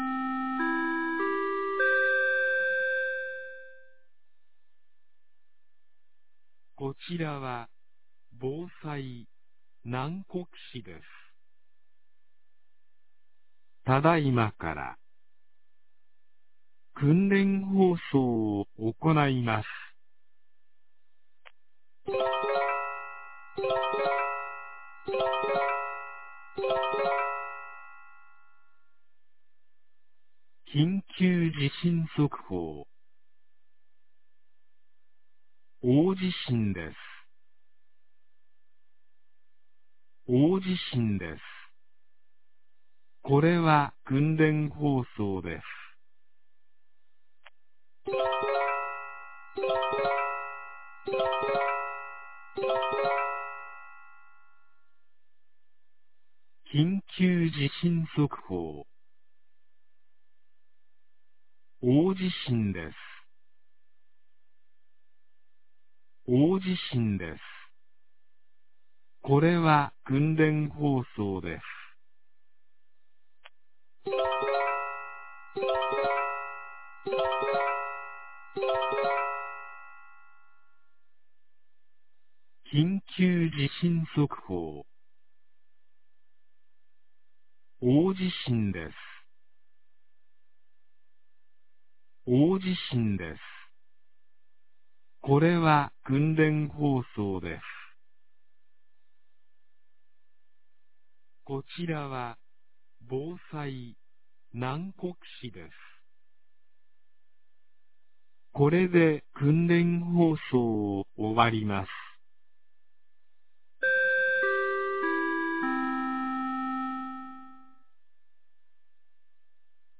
2023年06月15日 10時02分に、南国市より放送がありました。
放送音声